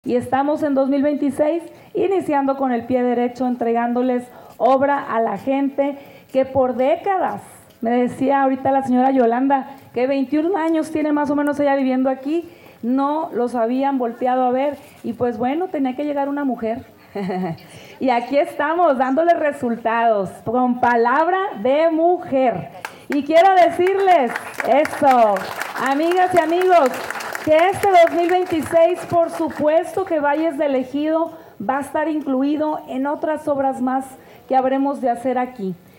En su mensaje, la primera mujer Presidenta de este puerto agradeció el recibimiento.